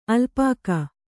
♪ alpāka